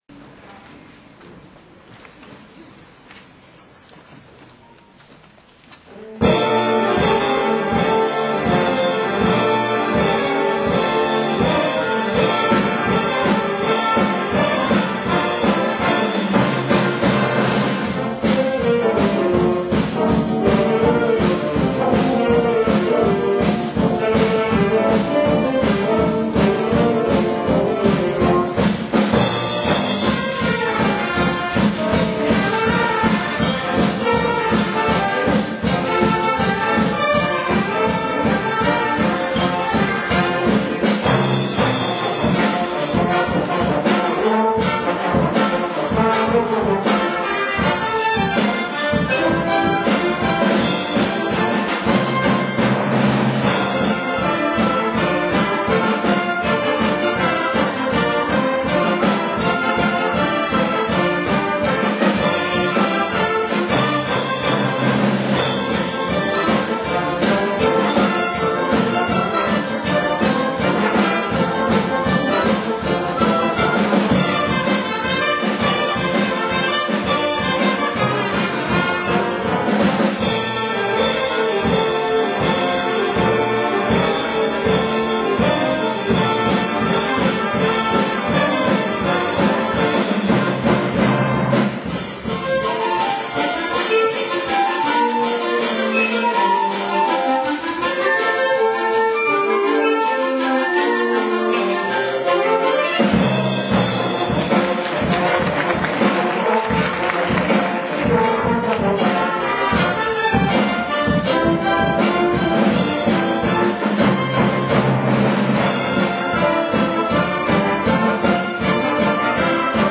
３月１２日（日） 京都市西文化会館ウエスティで「平成２８年度 ウエスティ吹奏楽祭」が行われました。 松尾中学校吹奏楽部は午後の部の３番目に出演しました。 ・「Happiness」 ・「エル・カミール・レアル」 ・「恋」 ・「Paradise Has No Border」 ・「マジック」 の５曲を迫力ある演奏で聴かせてくれました。